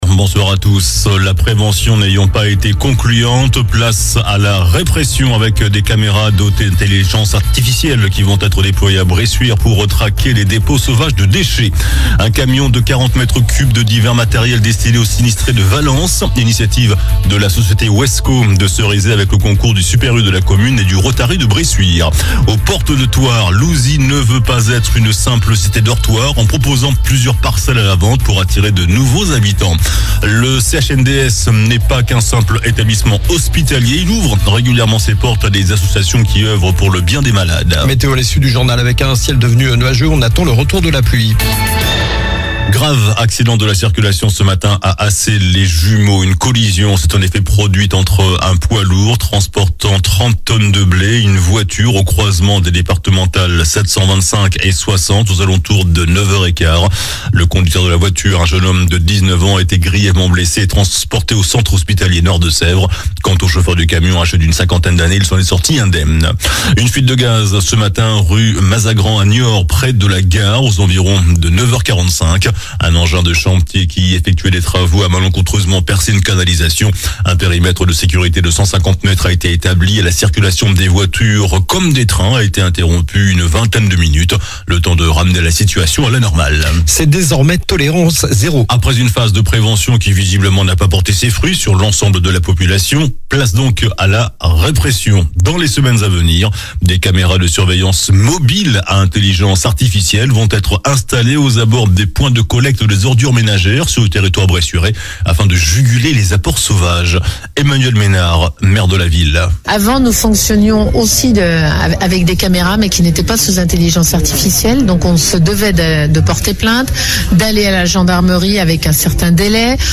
JOURNAL DU MARDI 21 JANVIER ( SOIR )